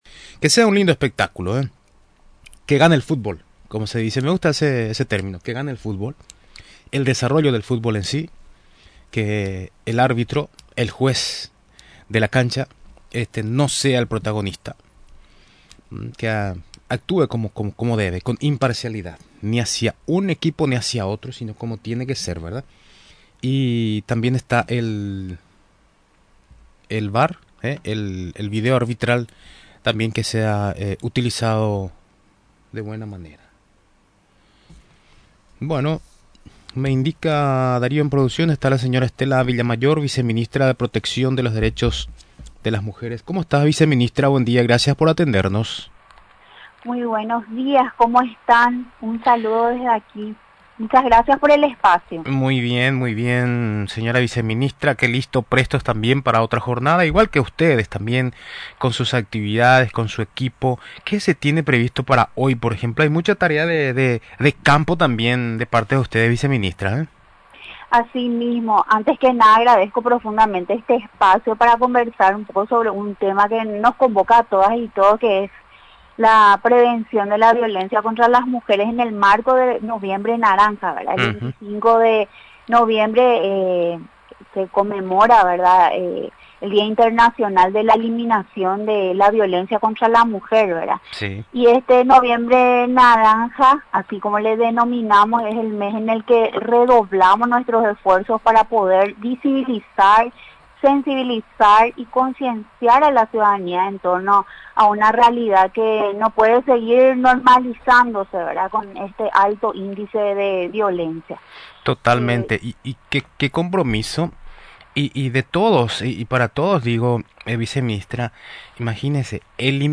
En entrevista con Radio Nacional del Paraguay, la Viceministra de Protección de los Derechos de las Mujeres, Stella Villamayor, destacó las acciones de la institución para la prevención de la violencia contra las mujeres.